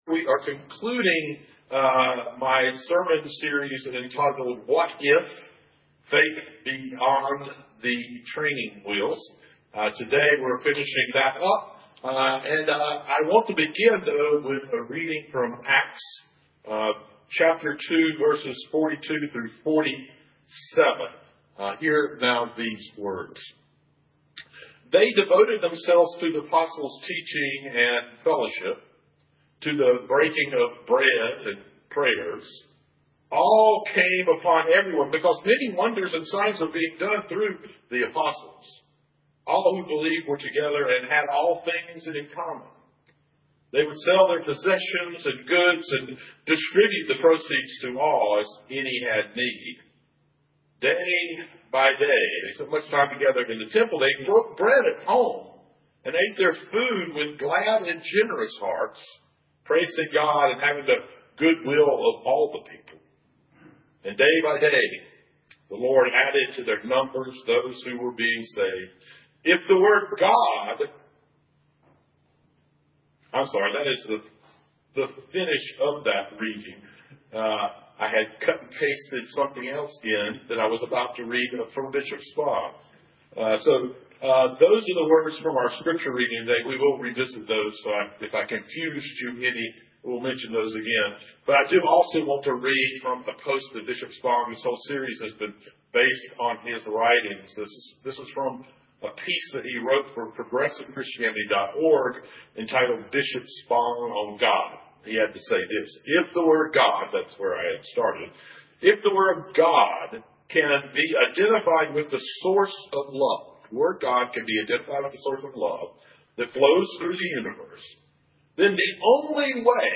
Sermon Series: What if? Faith Beyond the Training Wheels.